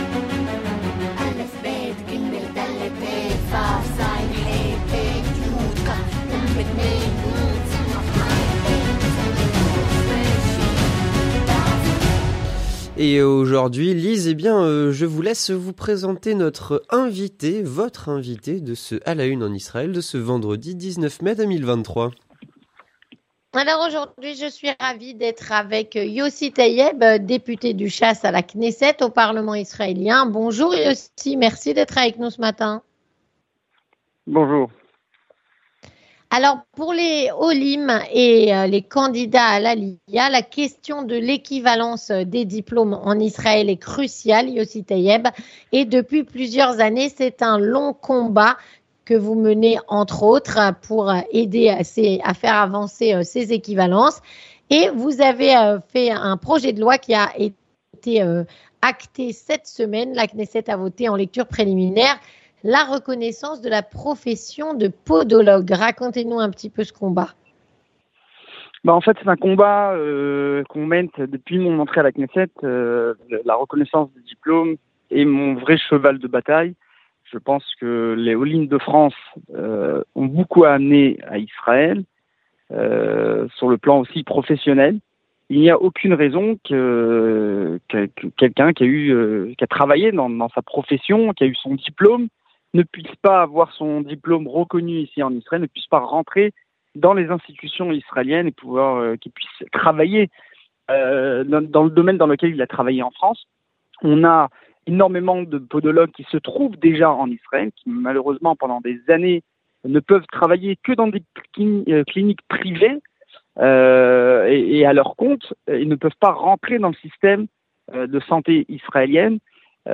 Avec Yossi Taïeb, député à la Knesset